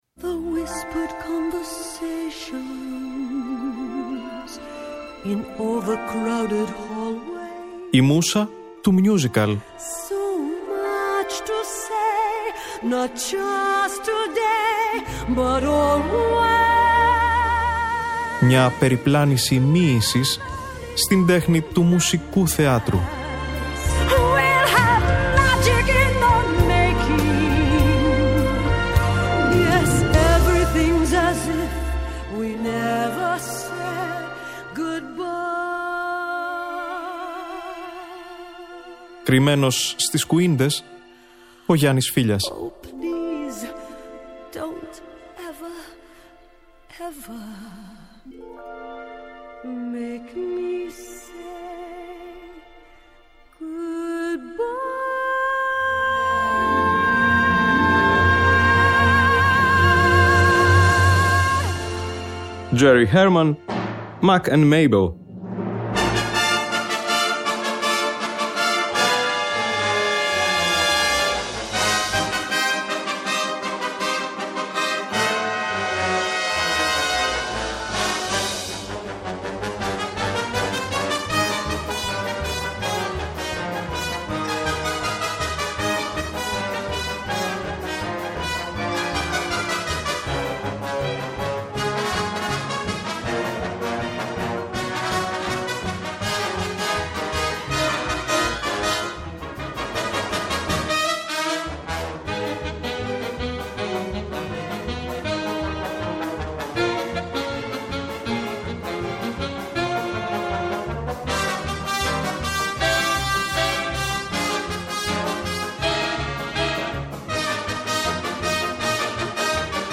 Από το Λονδίνο είναι και η ηχογράφηση του πρώτου cast του ‘95 , που απολαμβάνουμε στην εκπομπή της Τετάρτης 6 Μαρτίου στη «Μούσα του Musical» .